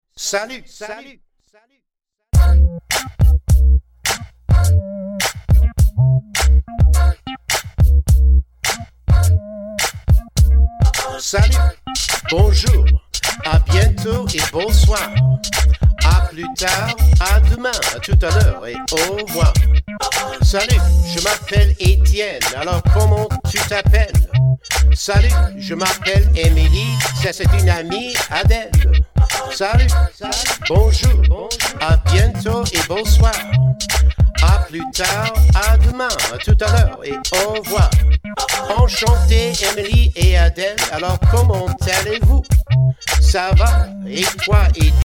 French Language Raps
Ten original raps on CD.